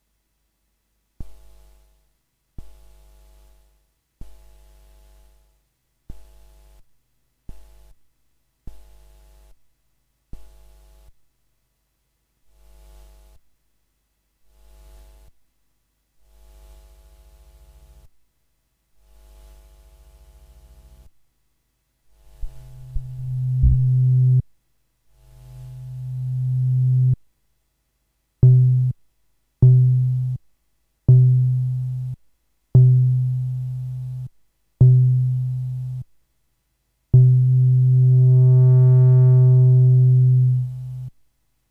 Hi guys,I recently bought a fantastic Korg Mono/Poly which unfortunately has a strange noise problem I didn't noticed when I picked it up.
Noise problem when key is pressed
The noise I'm talking about it's different from the typical noise floor every analog unit has. I tried to do the following test and I have attached an audio sample: First part: - All VCOs vol =0 / Cutoff =0 / Noise = 0 ( I change the attack and release time knobs in the vca section) Second part: - 1 VCO vol=50% / Noise =0 ( I change the cutoff and attack time knobs) As you can hear the noise is very audible at low cutoff value and it's not affected by cutoff, vco volumes, resonance.